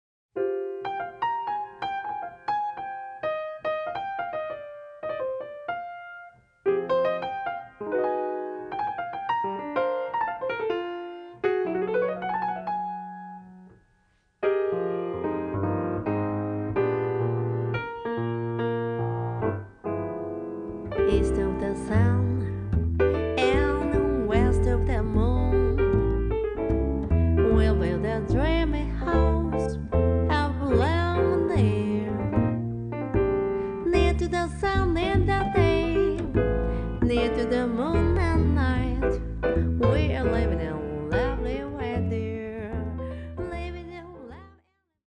guitar / vocal
bass
piano / rhodes / melodica